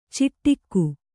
♪ ciṭṭikku